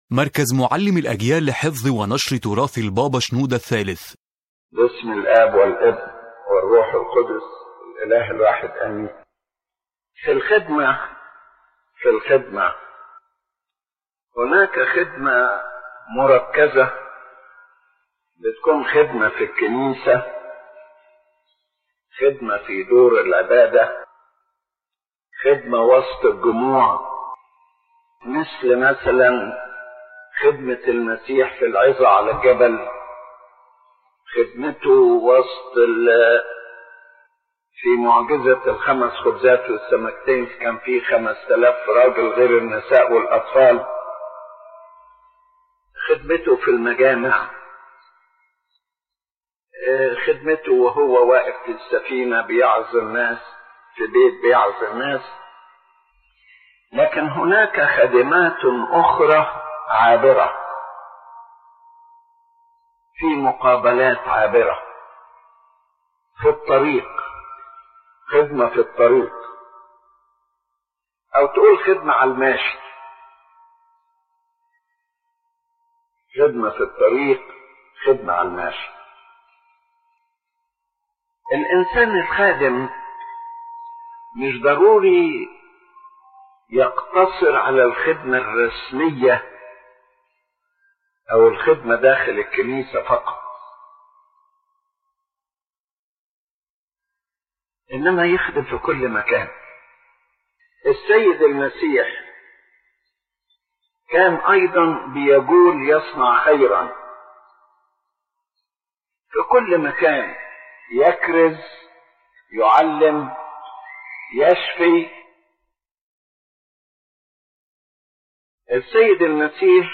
يتحدث قداسة البابا شنوده الثالث عن نوع من الخدمة لا يقتصر على الإطار الرسمي داخل الكنيسة، بل يمتد إلى كل مكان يسير فيه الإنسان. فليست الخدمة فقط في الاجتماعات أو العظات أو مدارس الأحد، بل هناك خدمة عابرة، تأتي في الطريق، في لقاء سريع، أو موقف مفاجئ.